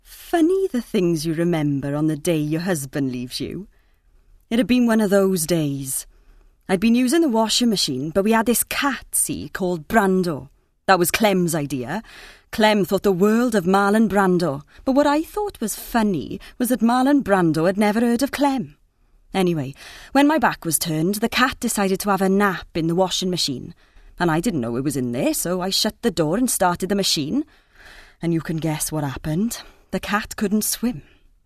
20s-40s. Female. Welsh.